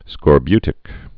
(skôr-bytĭk) also scor·bu·ti·cal (-tĭ-kəl)